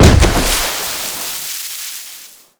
land_on_water_1.wav